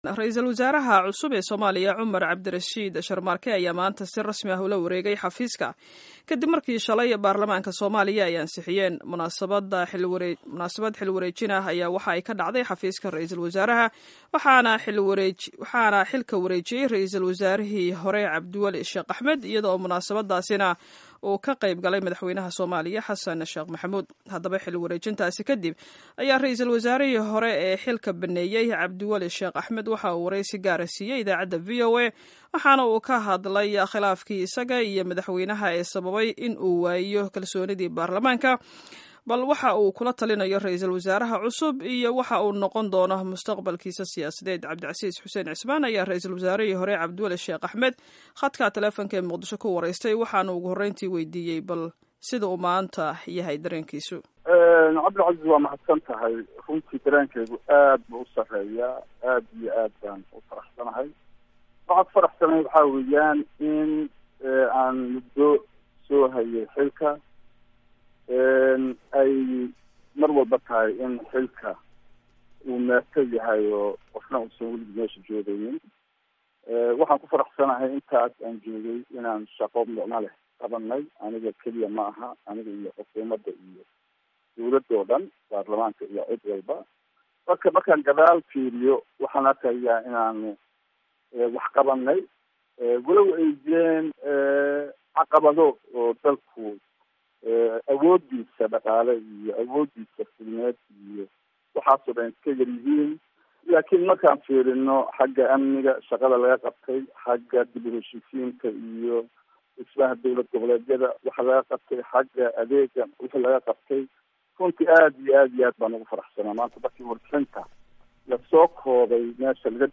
Waraysi Gaar ah: RW hore Cabdiweli Sheekh
Dhageyso wareysiga RW hore Cabdiweli Sheekh